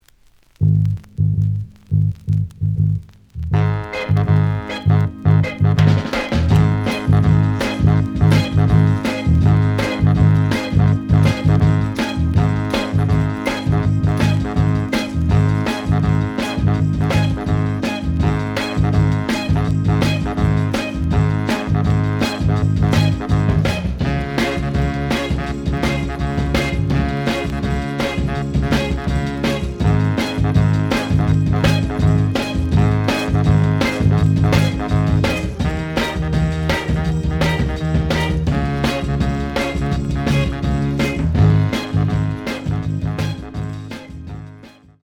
The audio sample is recorded from the actual item.
●Format: 7 inch
●Genre: Funk, 60's Funk
Slight edge warp. But doesn't affect playing.